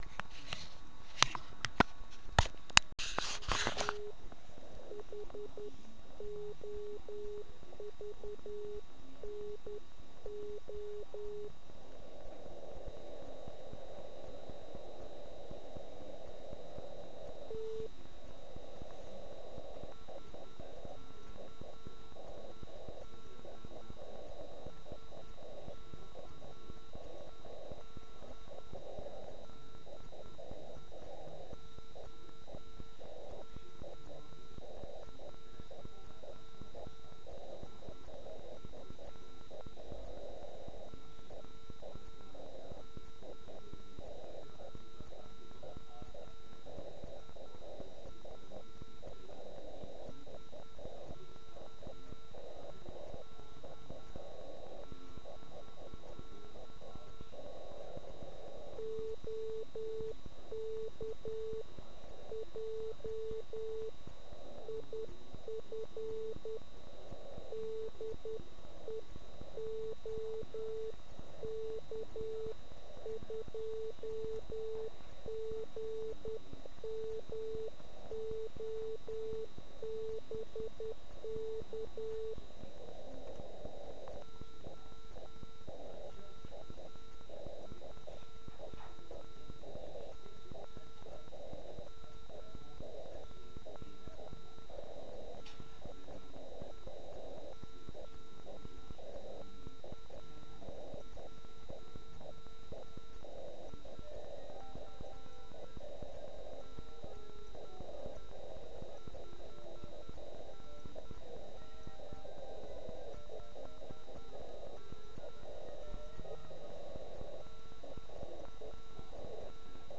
Tedy ano, pádlo a elektronický key.